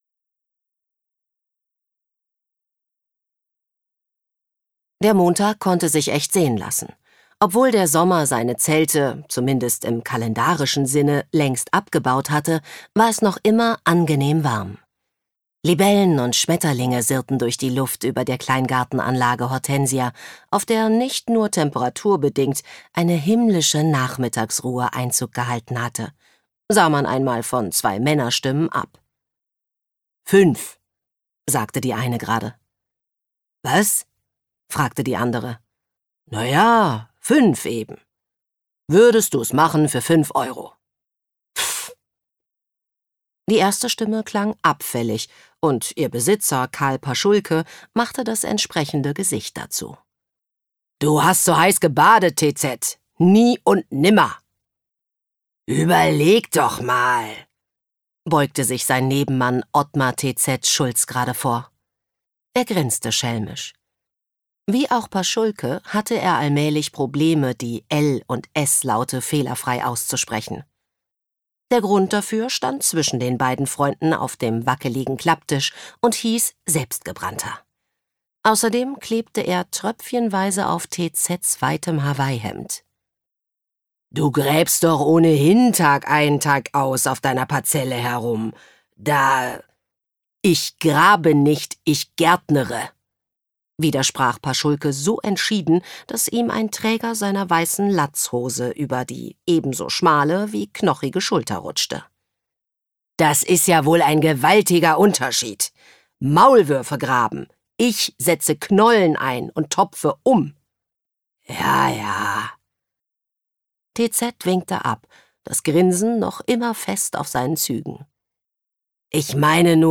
Hörbuch: